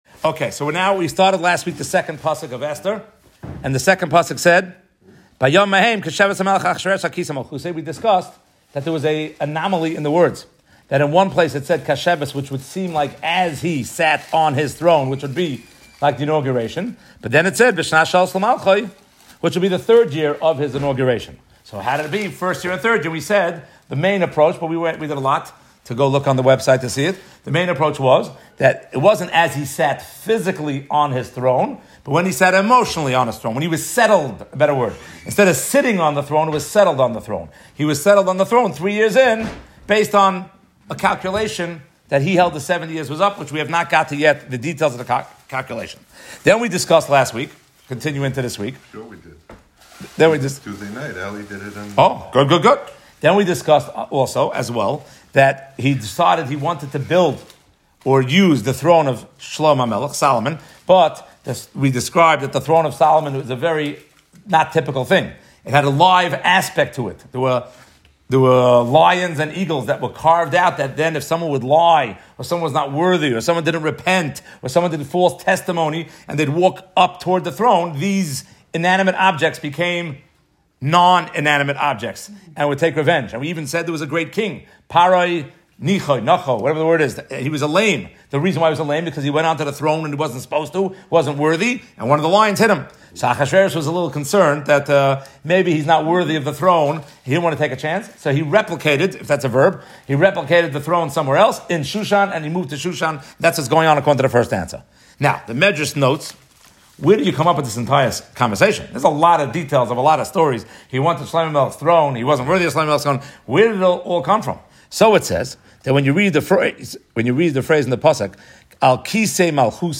From Young Israel Beth El, Brooklyn NY